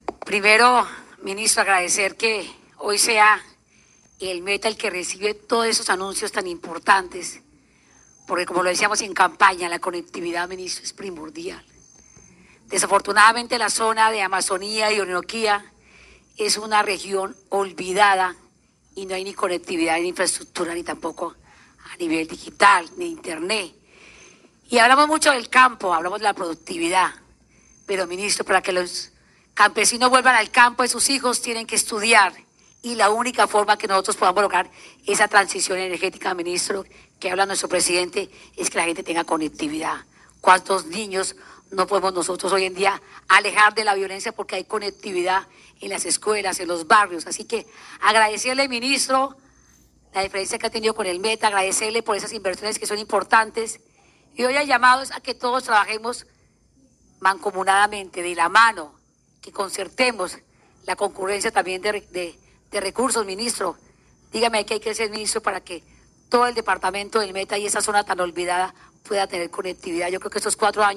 ☝🏻Audio gobernadora del Meta, Rafaela Cortés Zambrano.